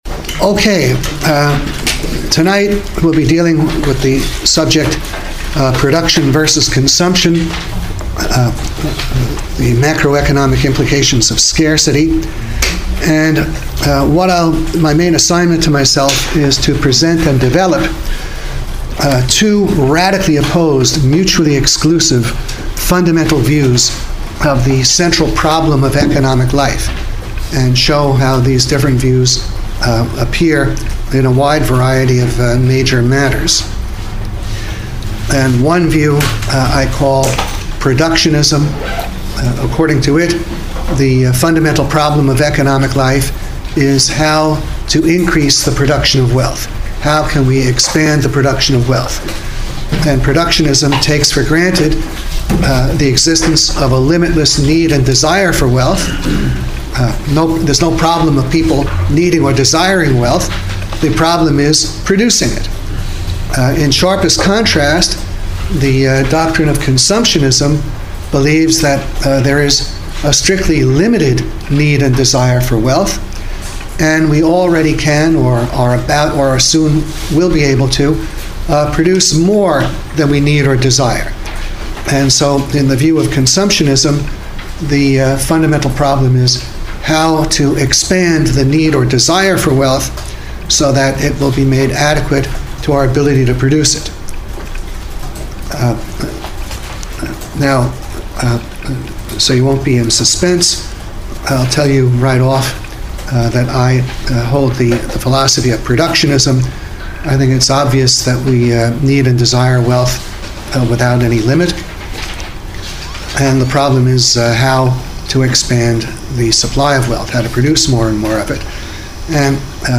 As an augment to the lectures available at George Reisman’s Program of Self-Education in the Economic Theory and Political Philosophy of Capitalism, below you may find audio files and youtube links to a series of lectures delivered by Dr. Reisman over a number of years at Pepperdine University, grouped into “Micro” and “Macro” topics.